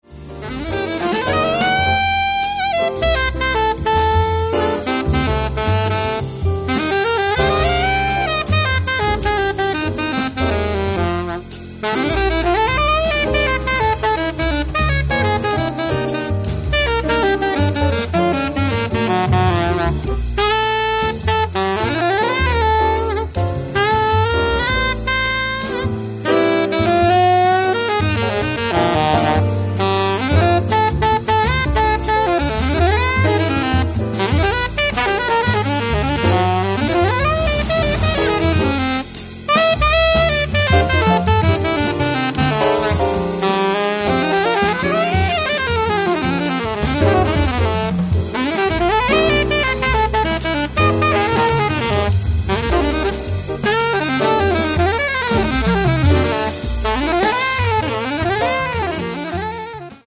sax alto e sopranino
pianoforte
contrabbasso
batteria